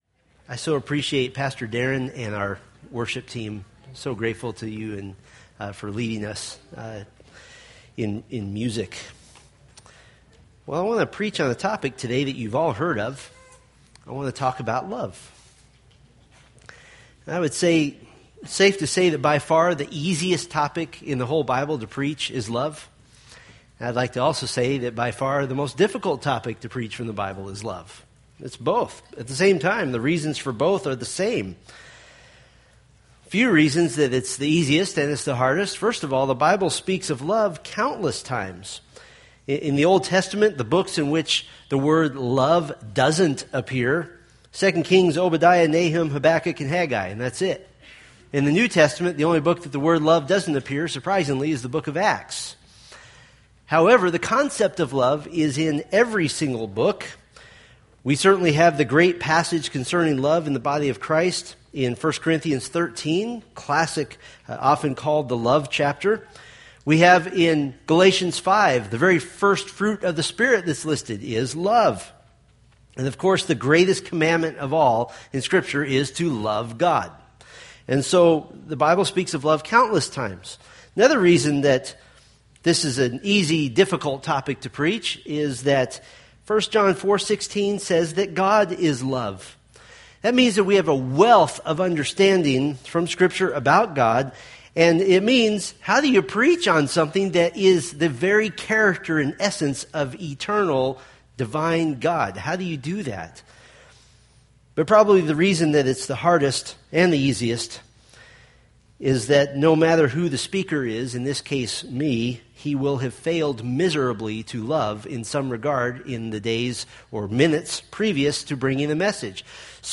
Homepage of Steadfast in the Faith, anchoring the soul in the Word of God by providing verse-by-verse exposition of the Bible for practical daily living.